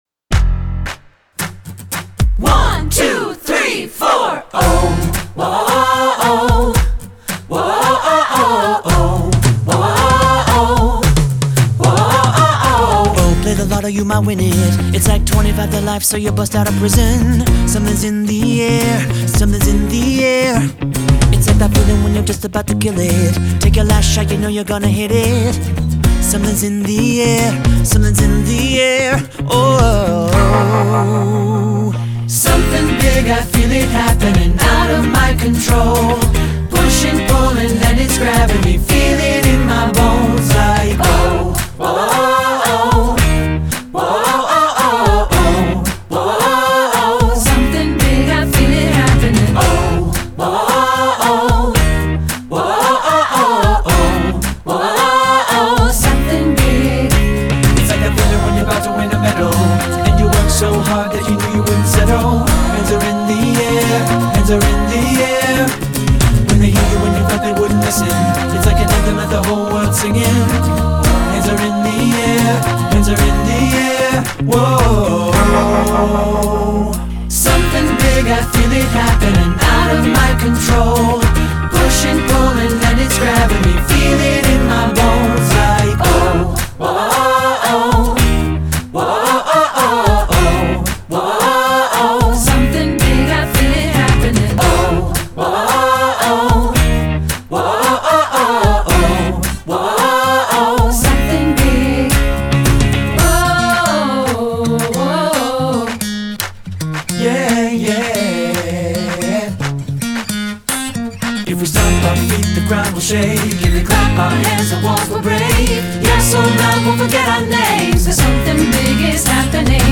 Choral Early 2000's Pop
3 Part Mix
3-Part Mixed Audio